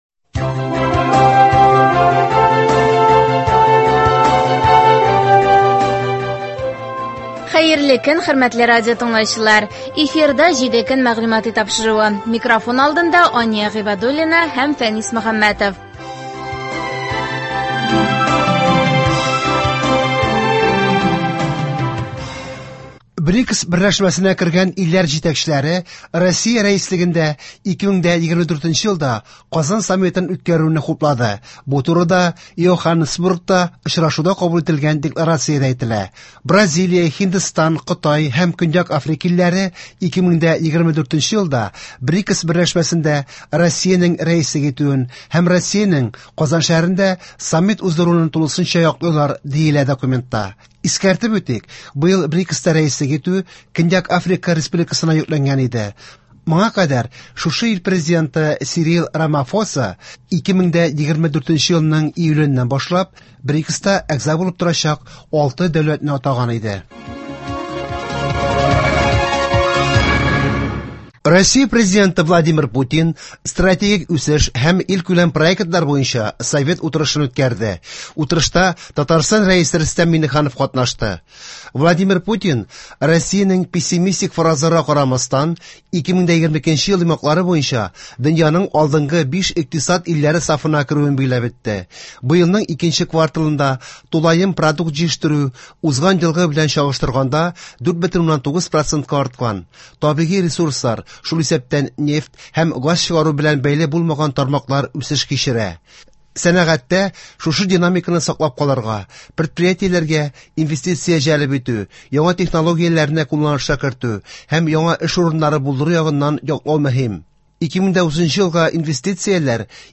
Атналык күзәтү.